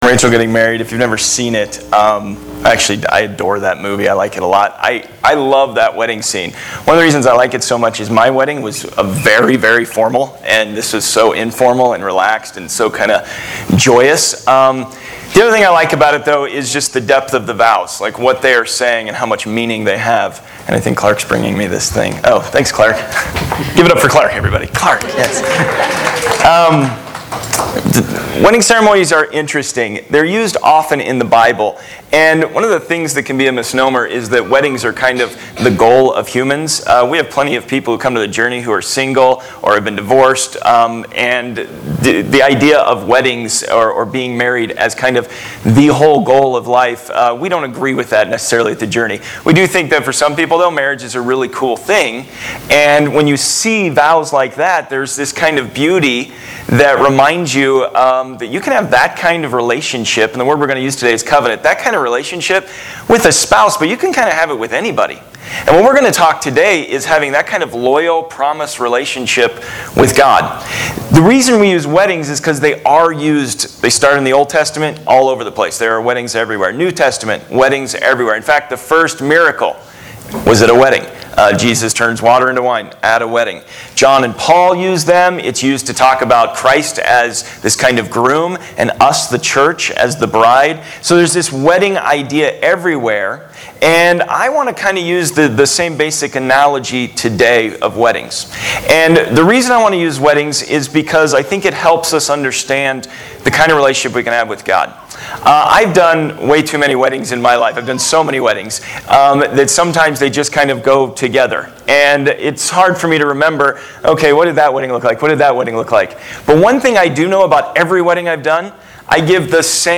Watch or listen to recent Sunday messages and series from The Journey Church in Westminster, CO. New sermons posted weekly with video and notes.